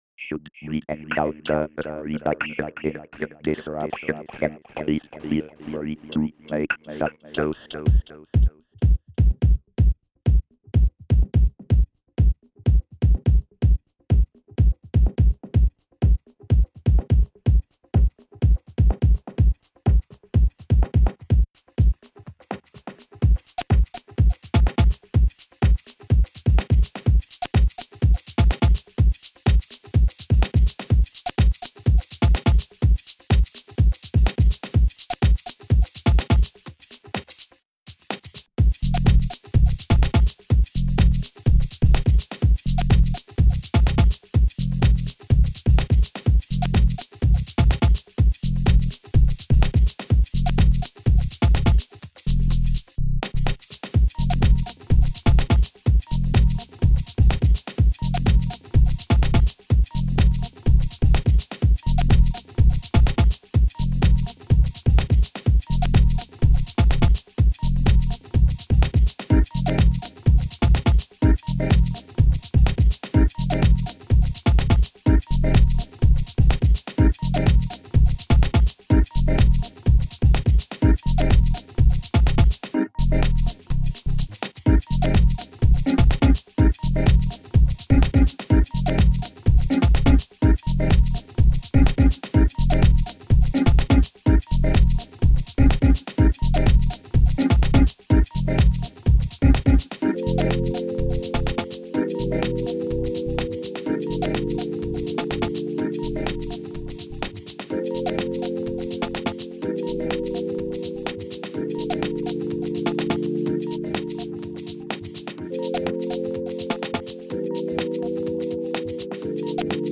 dance/electronic
house
Techno
Ambient